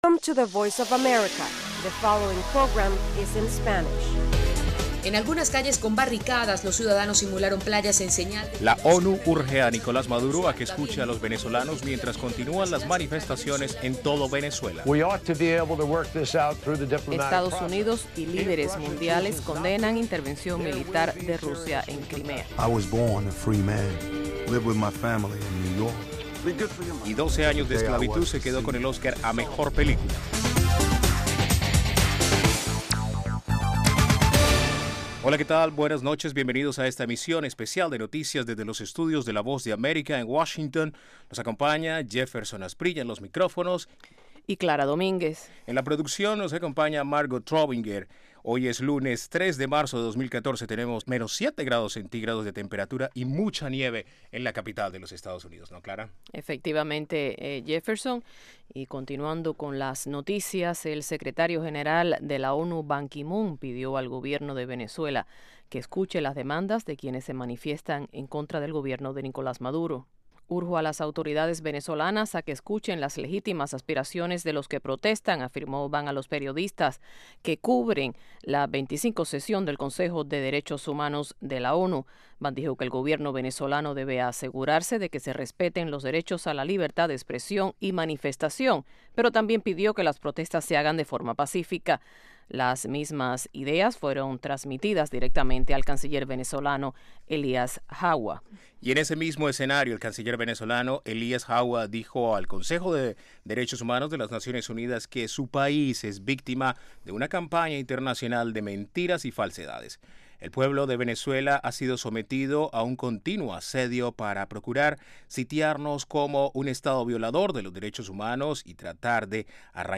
De lunes a viernes, a las 8:00pm [hora de Washington], un equipo de periodistas y corresponsales analizan las noticias más relevantes.